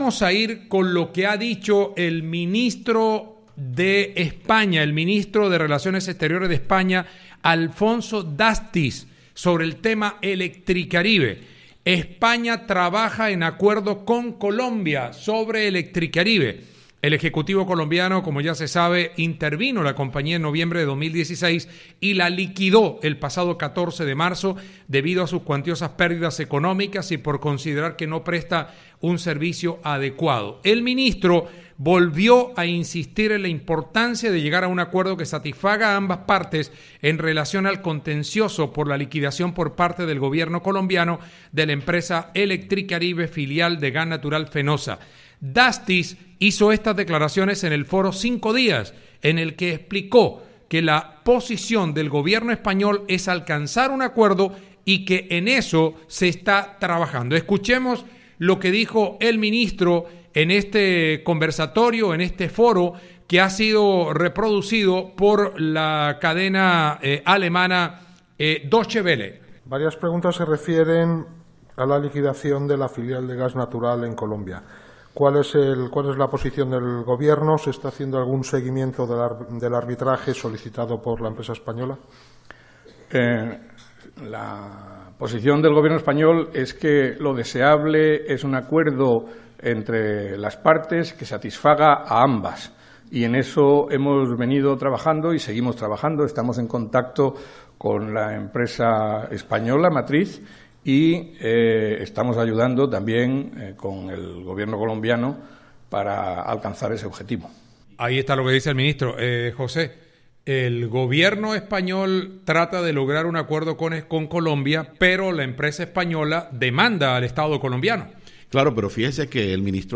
El funcionario hizo estas declaraciones en el Foro Cinco Días, en el que explicó que la posición del Gobierno español es alcanzar un acuerdo y que en eso se está trabajando.